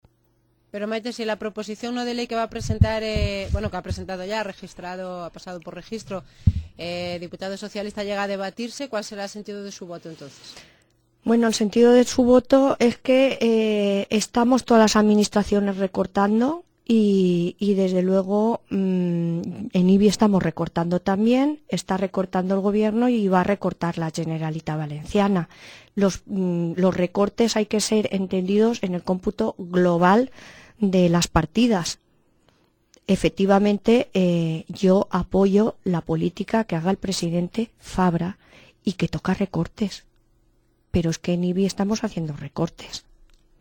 Esa pregunta me vino a la cabeza cuando un ciudadano de Ibi intervino en la entrevista de ayer martes a Maite Parra en Radio Ibi para preguntarle por qué los diputados valencianos cobran todos los meses y los trabajadores del Centro Ocupacional San Pascual no. Maite Parra se excusó respondiendo que eso no es competencia municipal sino de Generalitat, así que ella no podía decirle más.